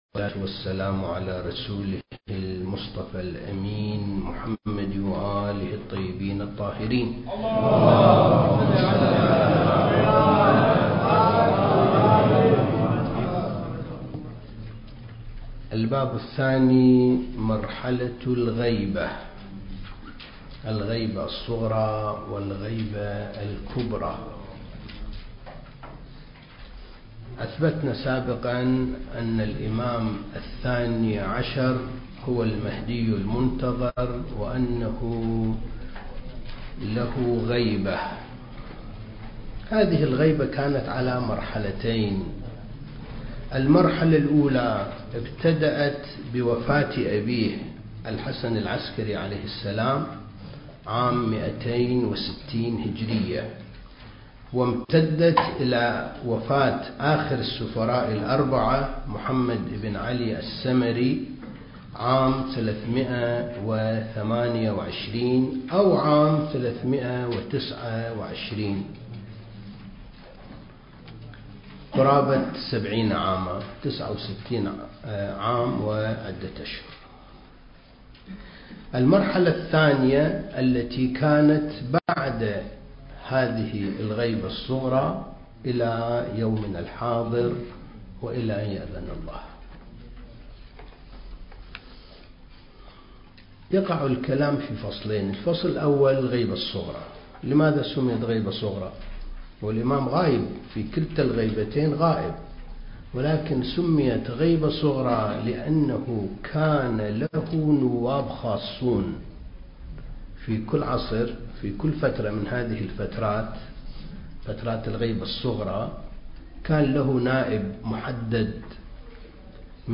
دورة الثقافة المهدوية (5) المكان: معهد وارث الأنبياء (عليهم السلام) لإعداد المبلغين العتبة الحسينية المقدسة